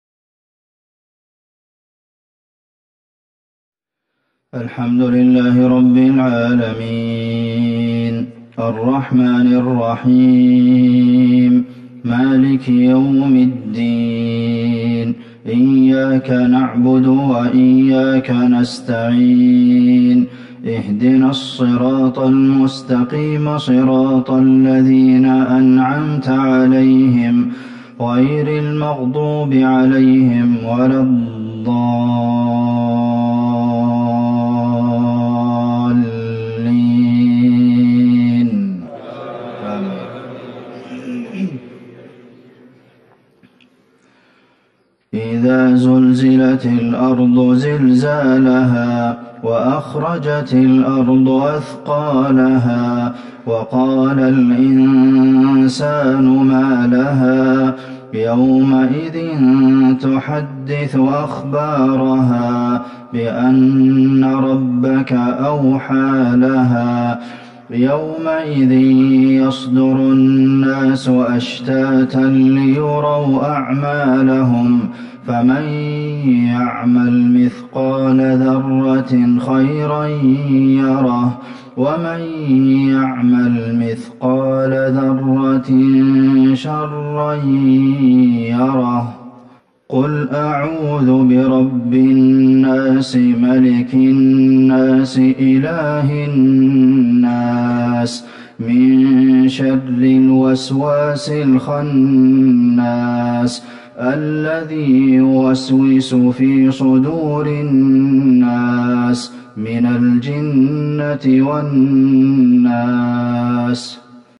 صلاة المغرب ١٩ جمادي الاولى ١٤٤١هـ سورة الزلزله والناس Maghrib prayer 7-1-2020 from Surat Al-Zalzalah and Alnaas > 1441 🕌 > الفروض - تلاوات الحرمين